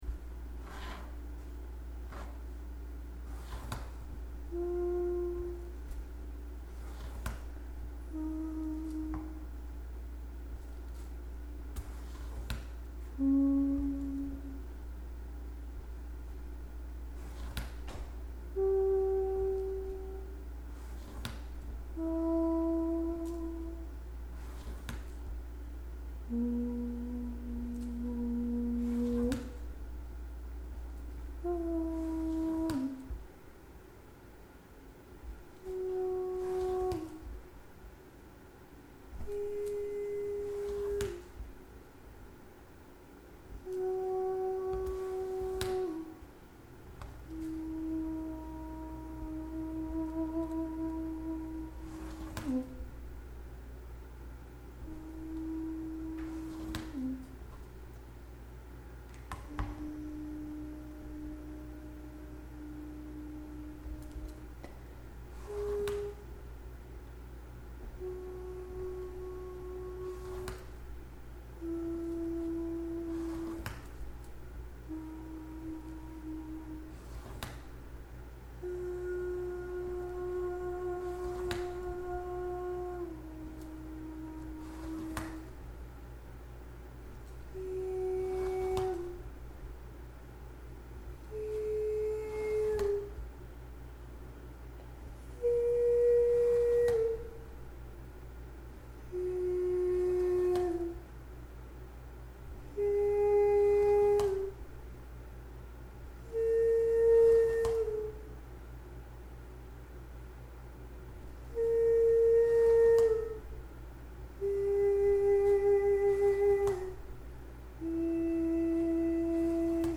Investigations of drawing as linear sound in varied spaces and also as affected by repeated actions when making a drawing. The recordings emphasize the rhythmic and musical aspects inherent in the construction of the work.
The sound tracks also reveal the process drawing as action.
Draw Track 10/23/2012 : while drawing